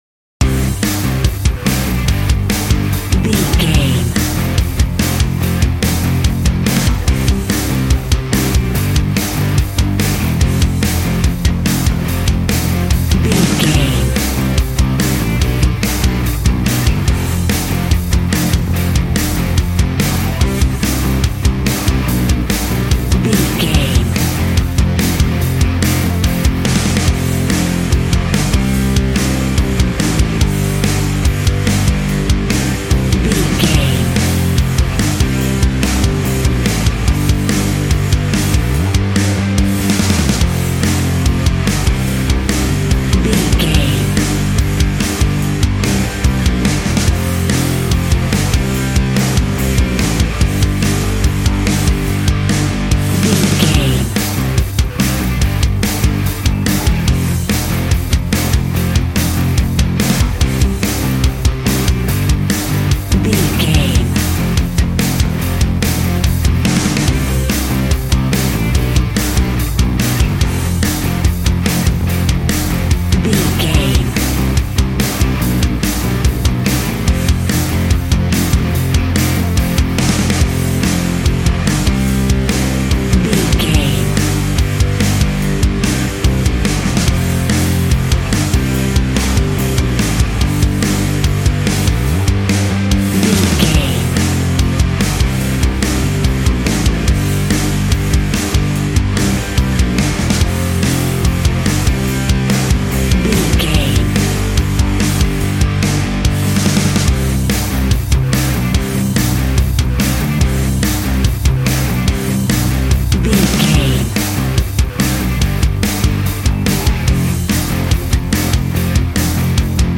Ionian/Major
angry
heavy
aggressive
electric guitar
drums
bass guitar